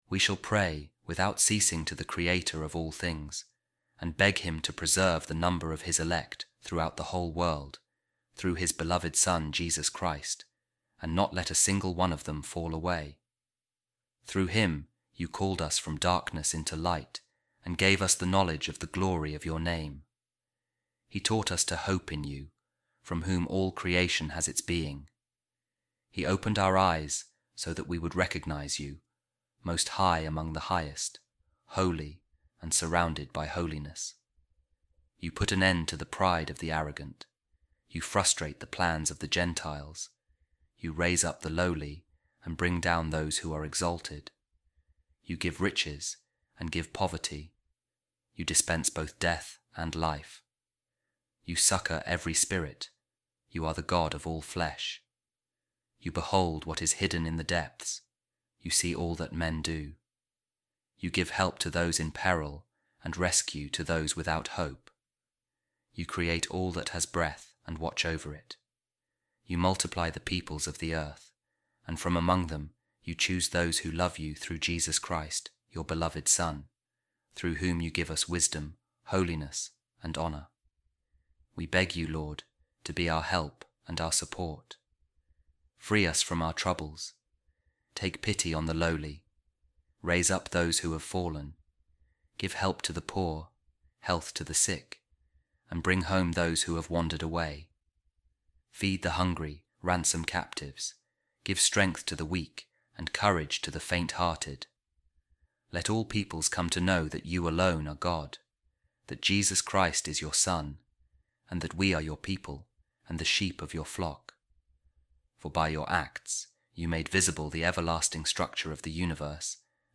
Office Of Readings | Week 1, Monday, Ordinary Time | A Reading From The Letter Of Pope Saint Clement I To The Corinthians | The Word Of God Is The Found Of Wisdom On High